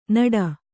♪ naḍa